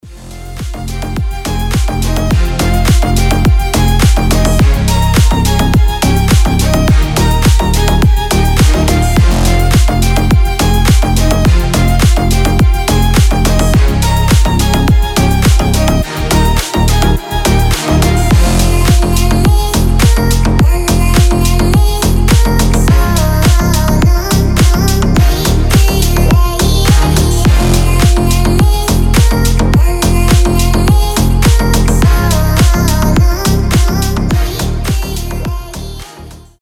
• Качество: 320, Stereo
громкие
скрипка
басы
slap house
Громогласный рингтон с басами и скрипкой